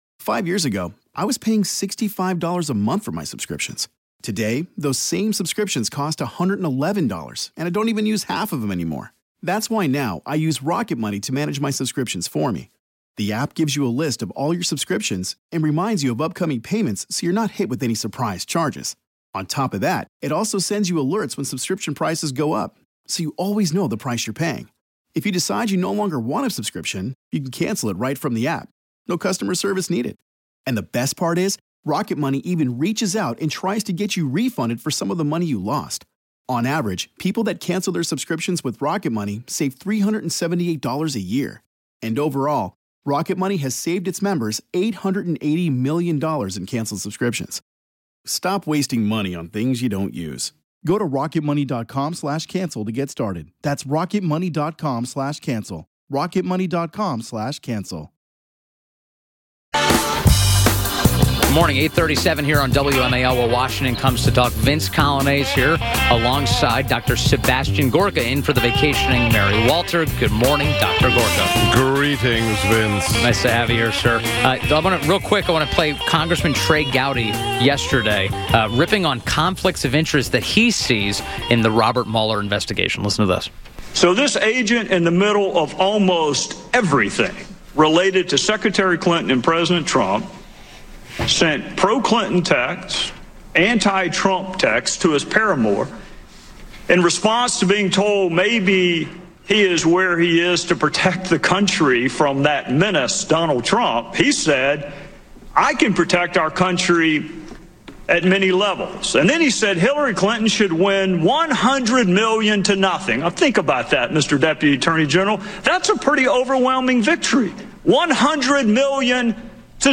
WMAL Interview - ANDREW MCCARTHY - 12.14.17